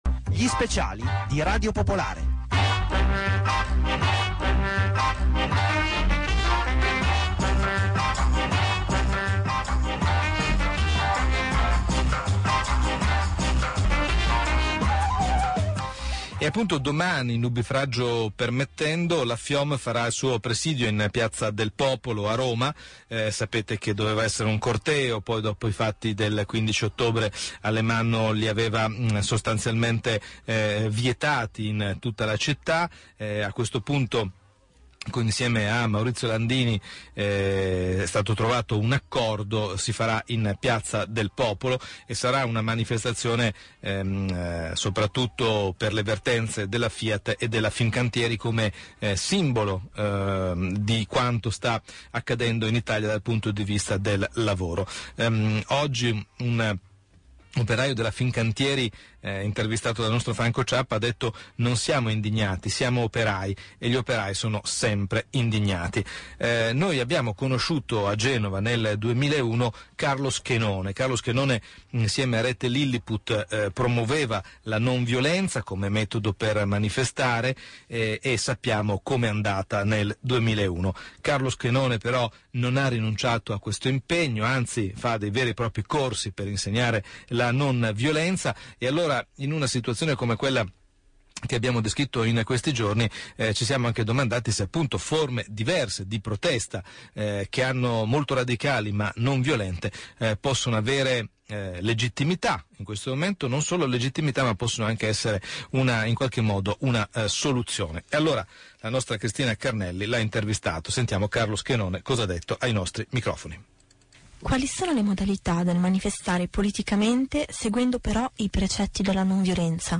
Intervista del 20 ottobre 2011 su Radio Popolare